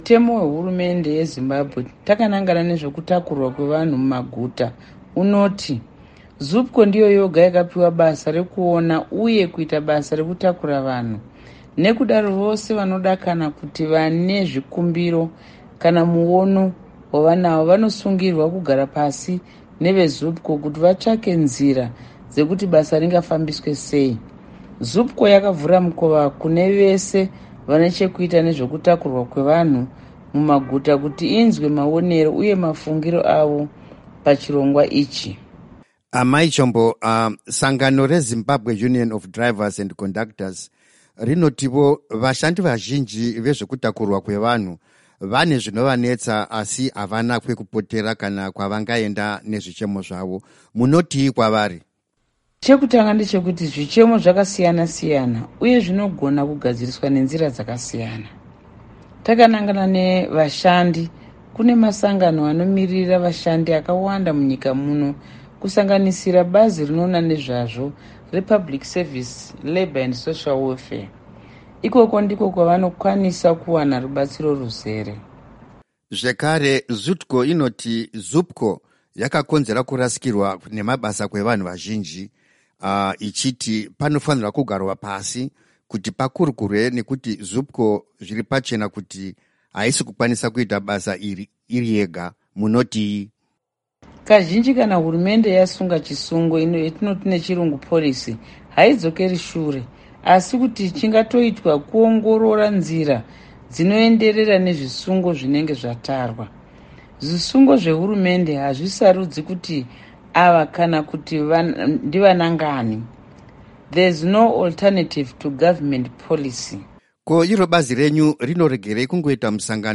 Hurukuro naAmai Marian Chombo